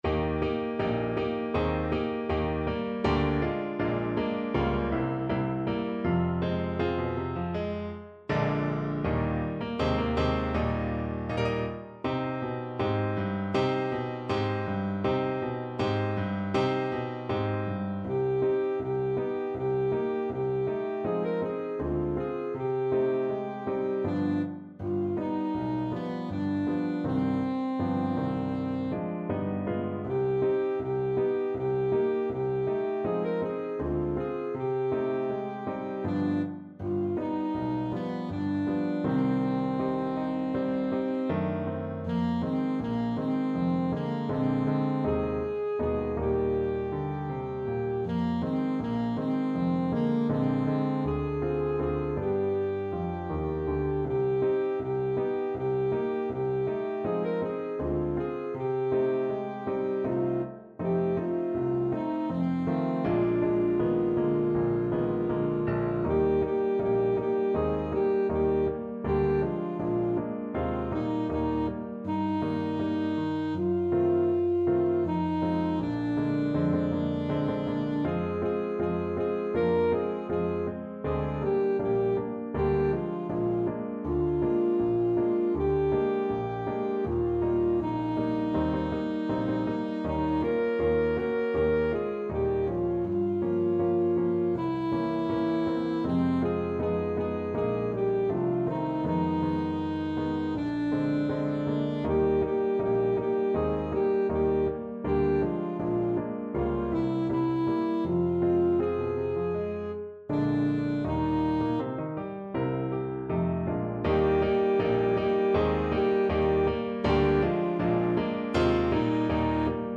Alto Saxophone
2/2 (View more 2/2 Music)
~ = 160 Moderato
Bb4-Bb5
Pop (View more Pop Saxophone Music)